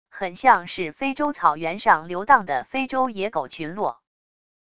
很像是非洲草原上流荡的非洲野狗群落wav音频